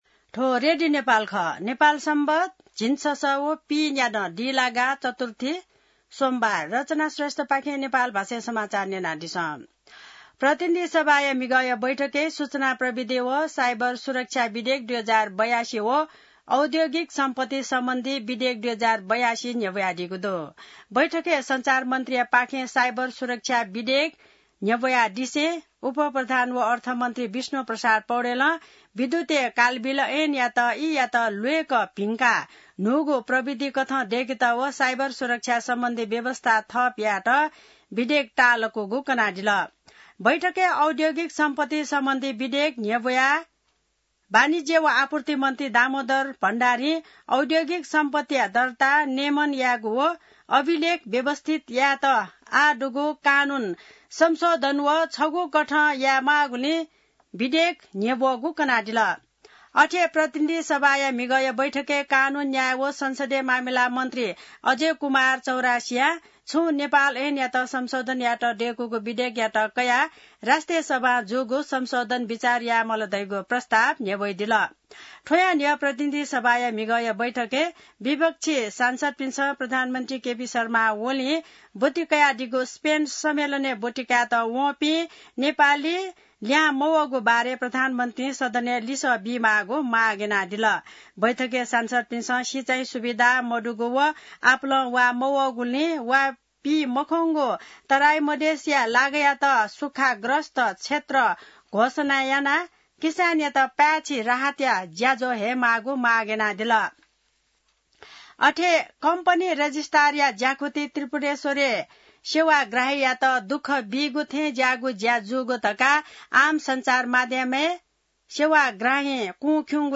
नेपाल भाषामा समाचार : ३० असार , २०८२